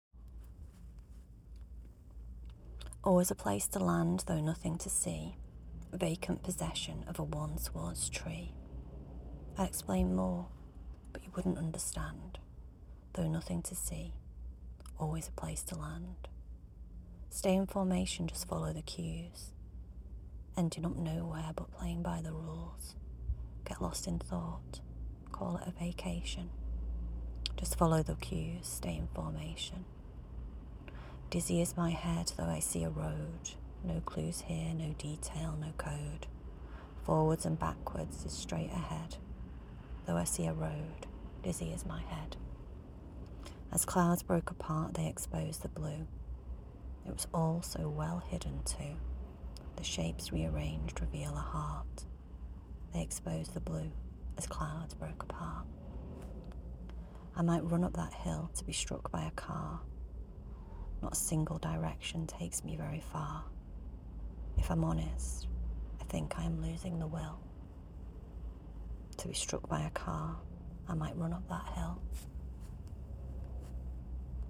And as always you read it so beautifully! You have a voice that will be admired by many!